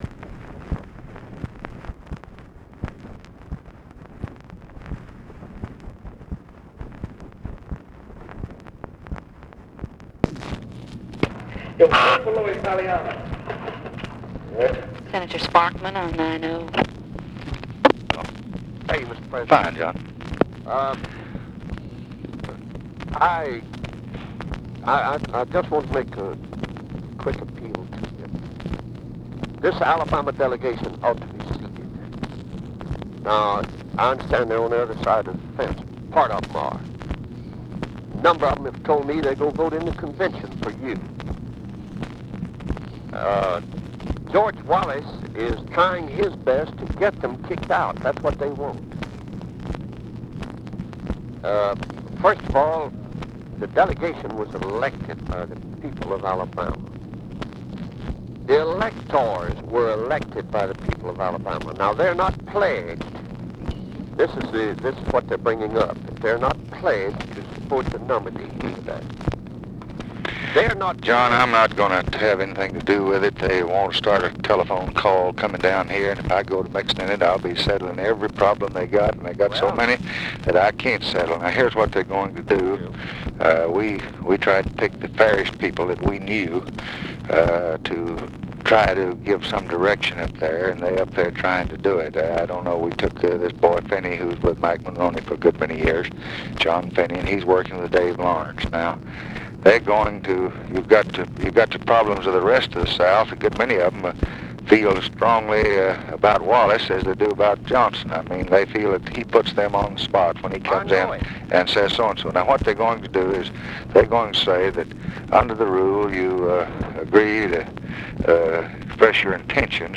Conversation with JOHN SPARKMAN, August 22, 1964
Secret White House Tapes